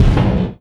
Energy Fx 07.wav